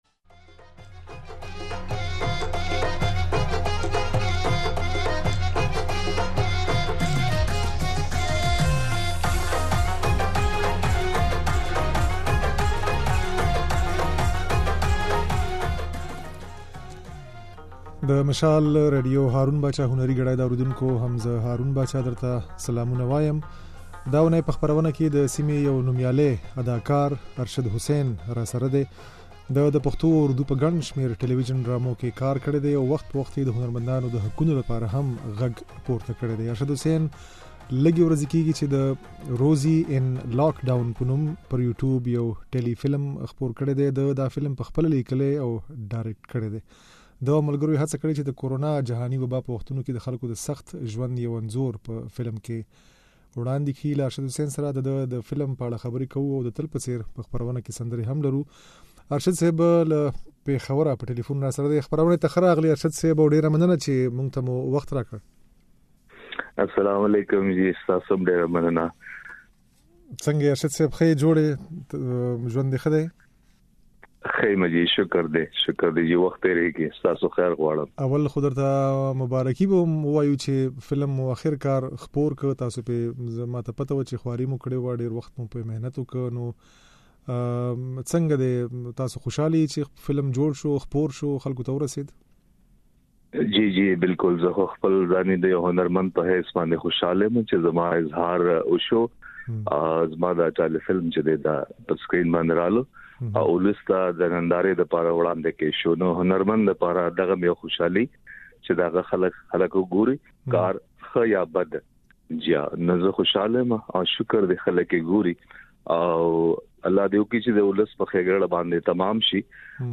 ځينې سندرې هم اورېدای شئ